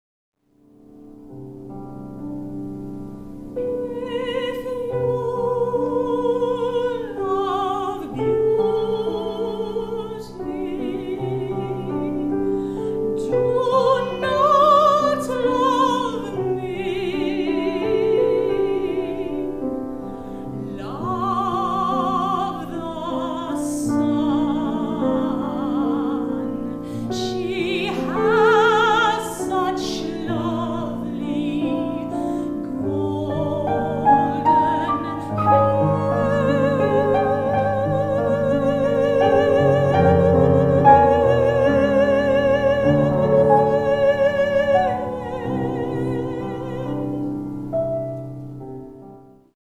mezzo-soprano & piano